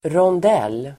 Uttal: [rånd'el:]